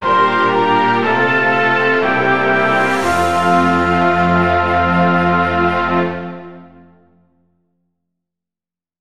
Success Triumph Resolution Sound Effect
game over positive relax resolution satisfaction success triumph sound effect free sound royalty free Sound Effects